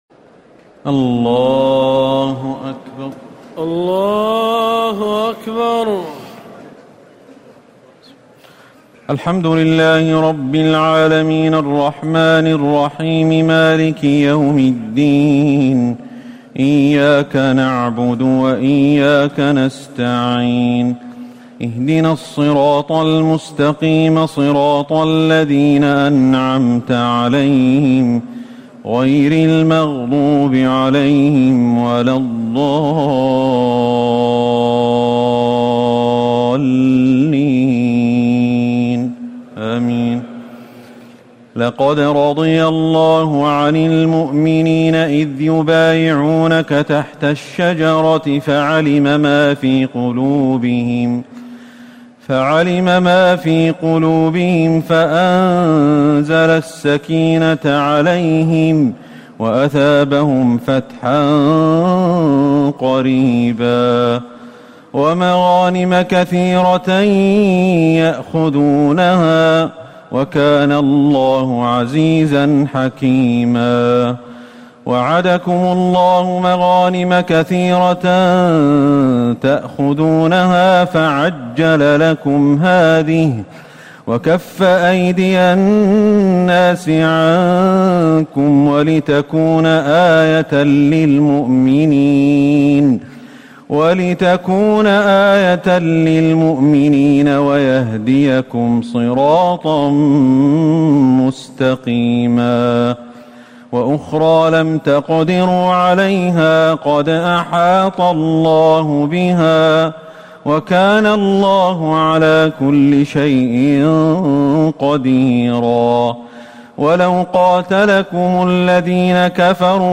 تراويح ليلة 25 رمضان 1439هـ من سور الفتح (18-29) الحجرات و ق و الذاريات (1-23) Taraweeh 25 st night Ramadan 1439H from Surah Al-Fath and Al-Hujuraat and Qaaf and Adh-Dhaariyat > تراويح الحرم النبوي عام 1439 🕌 > التراويح - تلاوات الحرمين